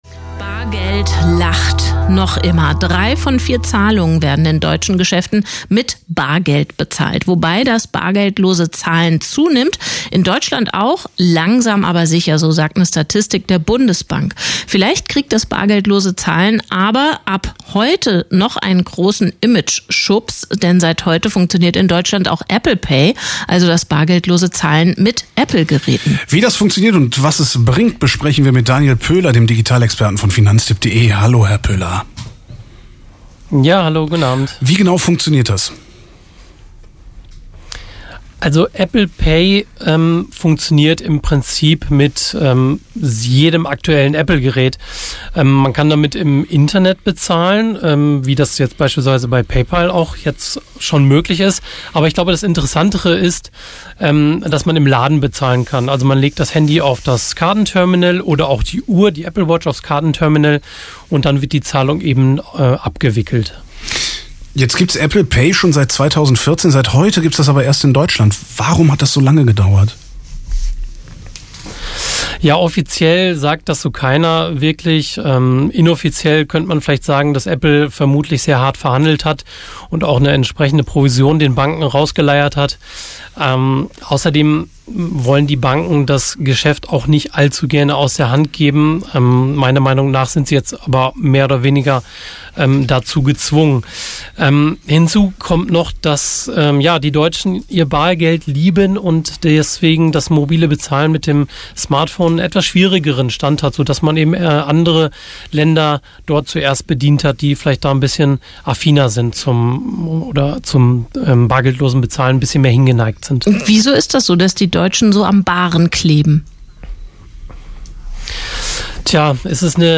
Bargeldloses Zahlen mit Apple Pay (Live-Schalte mit leichtem Versatz)